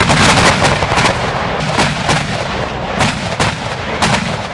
半自动 " 半自动步枪3
描述：一把半自动步枪，没有 使用bitwig，我使用10个不同的录制枪声层进行了eq'ed和多波段压缩，以形成新的枪声。 Subbass是为超低端合成的。 我使用的过程是使用高通和低通滤波器从低频，中频和高频的录音中选择频率。这形成了“分层声音” 在最初的枪声之前放了一个底鼓，让它打了一拳，让它听起来更大。 请享用。
声道立体声